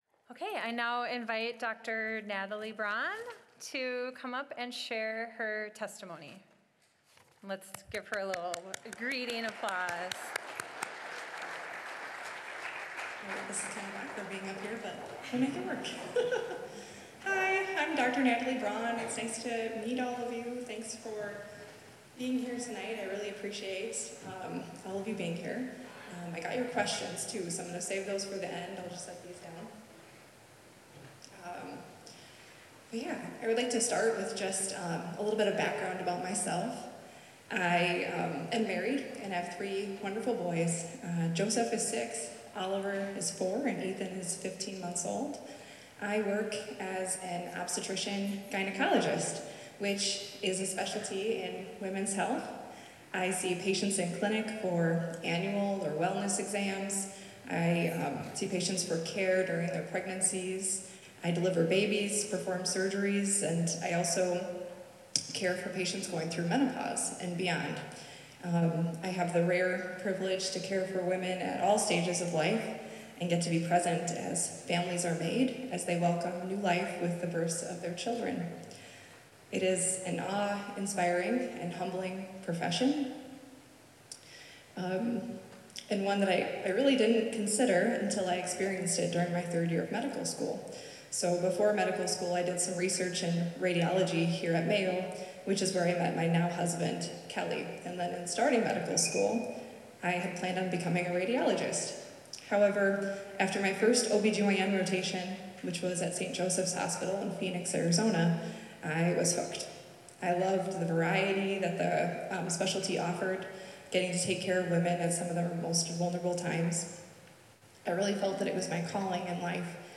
Talk on Respecting the Dignity and Sanctity of Life